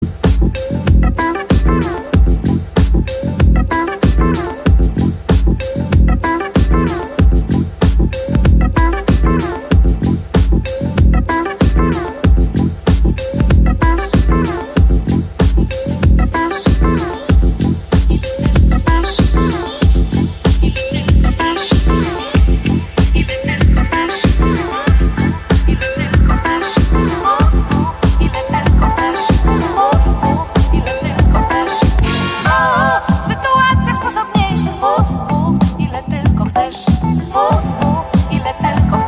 Kategorie Zwierzęta